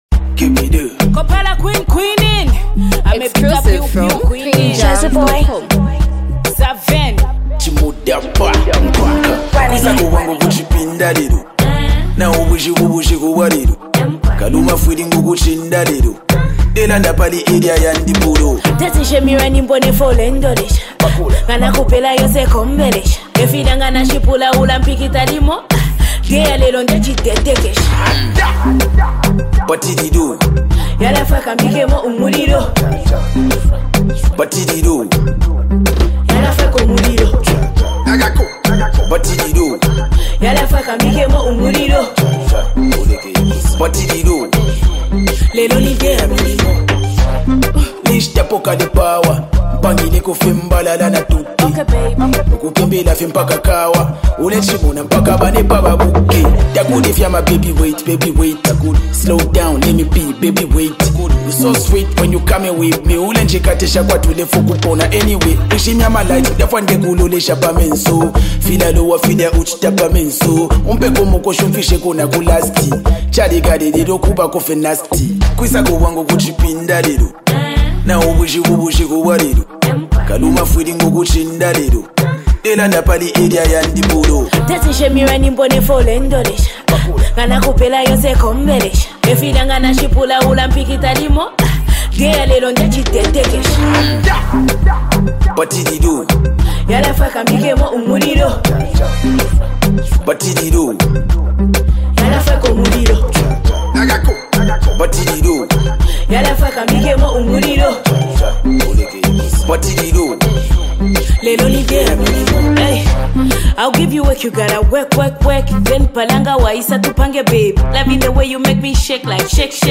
hype, street vibes, and a powerful hook
unique rap flow